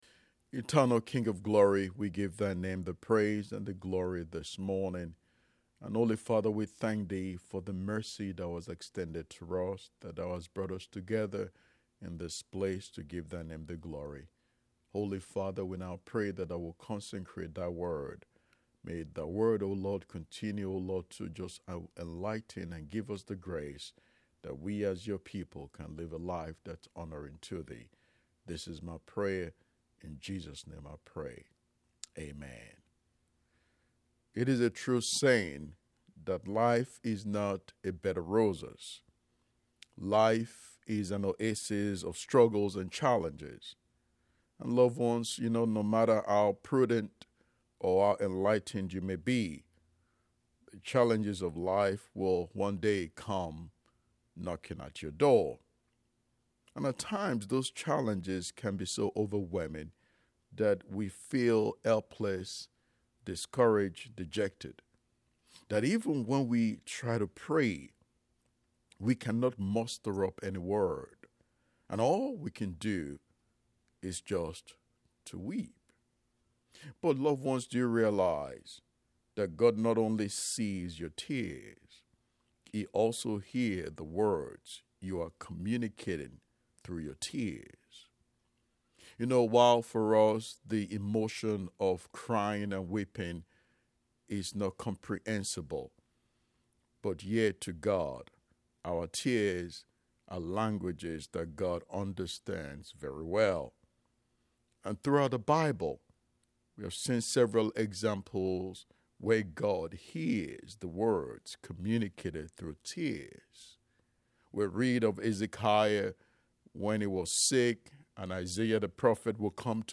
WAVA 105.1 FM - Sat Feb 21st and Sun Feb 22nd 2026 I Hear Your Cry - Touch of Love Bible Church